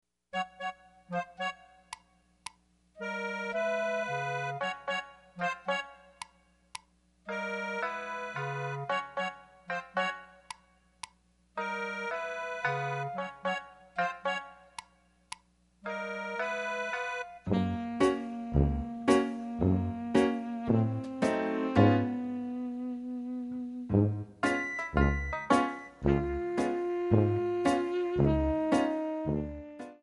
Backing track files: Jazz/Big Band (222)